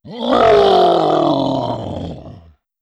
02. Ferocious Roar.wav